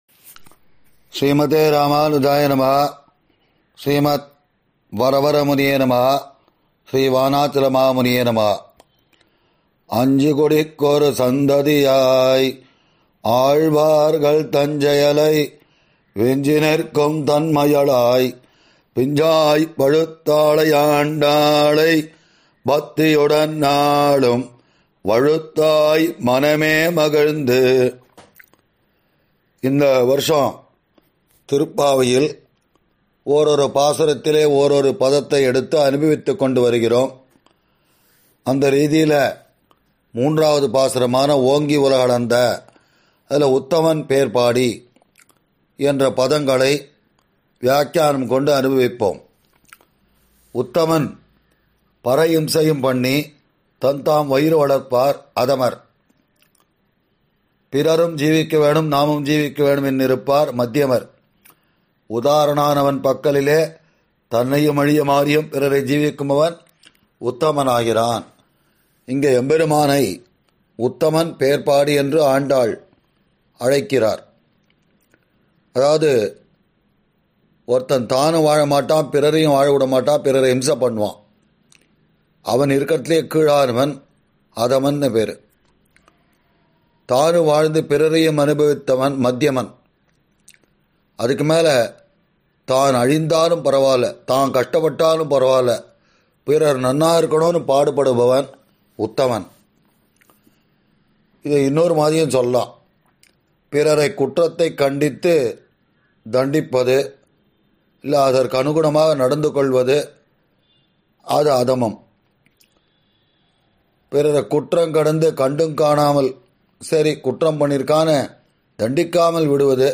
ஆறெனக்கு நின் பாதமே சரண் குழுமத்தினர் வழங்கும் சார்வரி ௵ மார்கழி ௴ மஹோத்ஸவ உபன்யாசம்